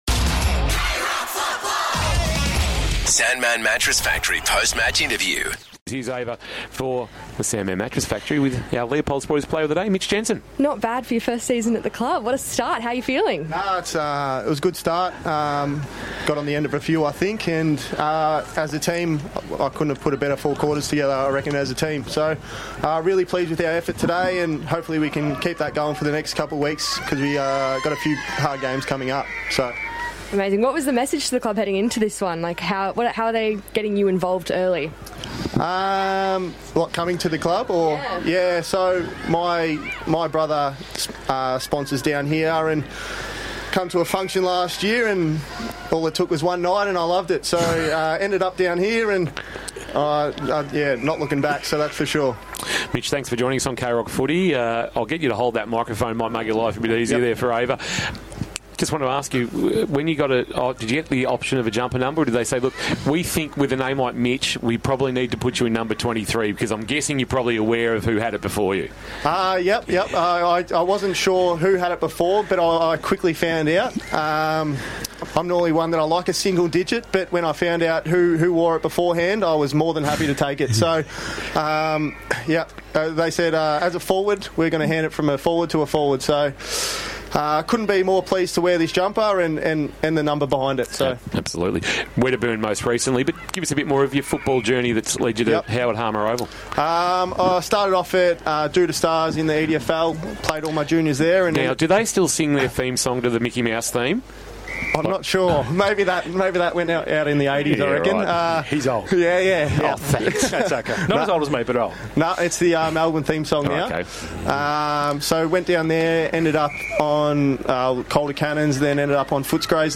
2026 - BFNL - Round 3 - Barwon Heads vs. Anglesea - Post-match interview